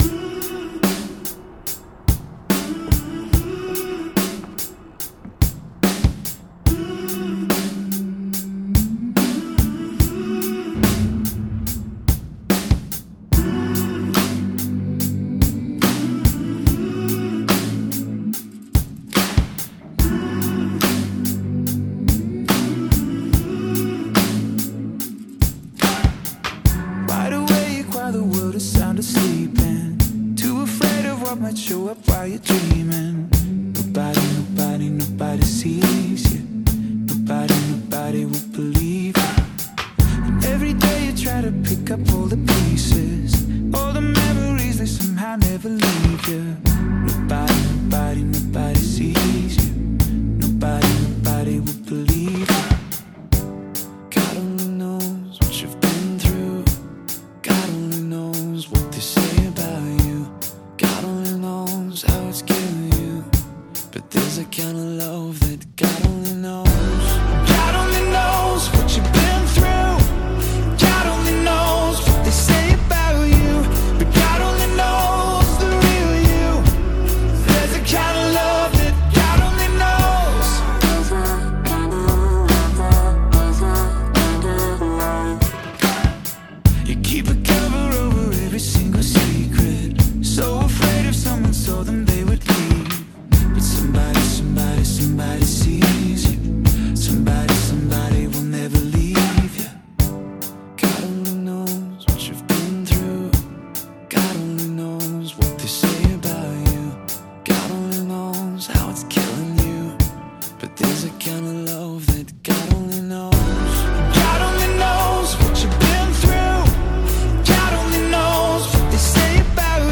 Drum Lessons